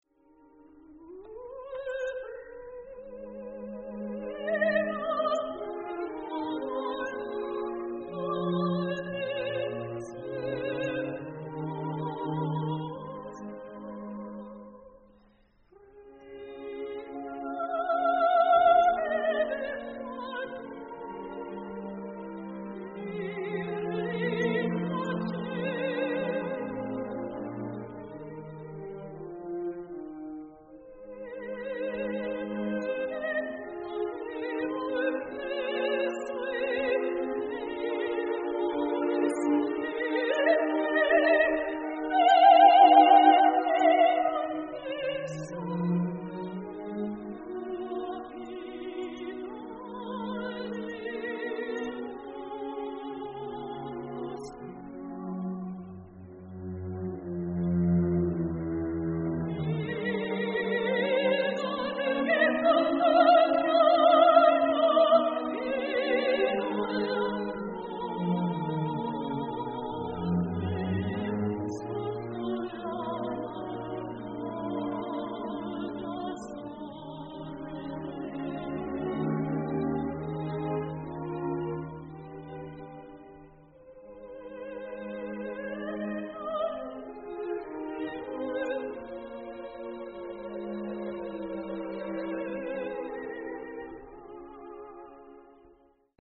Soprano
Chorus Pro Musica and the Concert Opera Boston